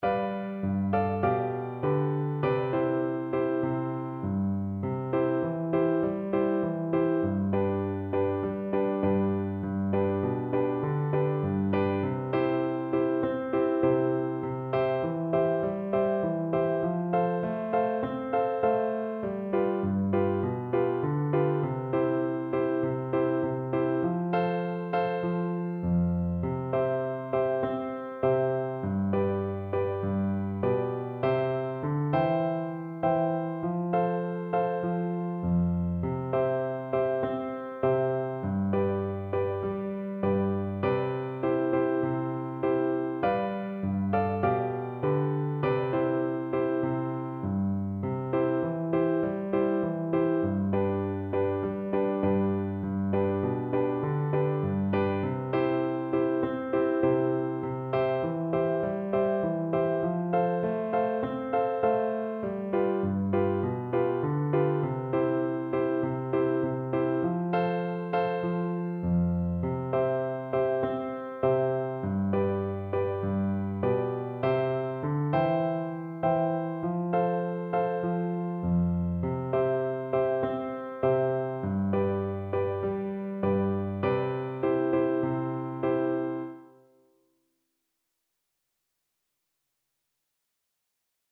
Gently =c.100
4/4 (View more 4/4 Music)
G5-A6
world (View more world Flute Music)